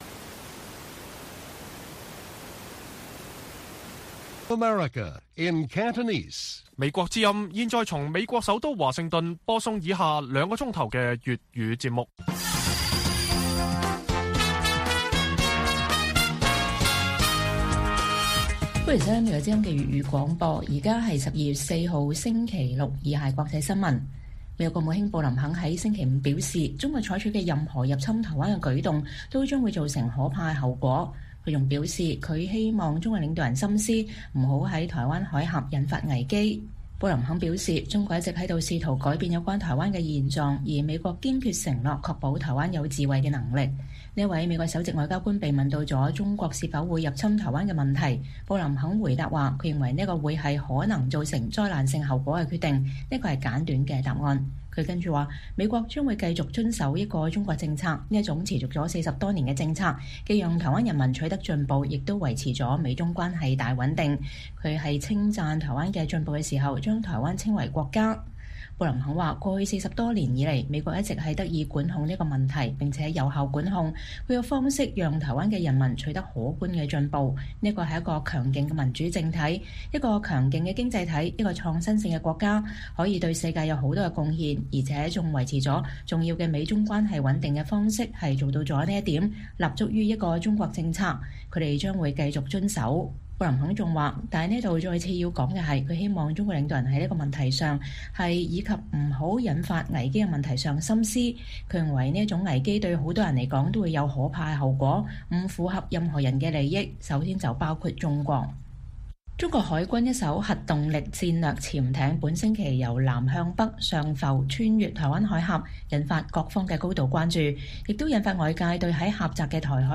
粵語新聞 晚上9-10點:布林肯：任何中國入侵台灣之舉將有“可怕後果”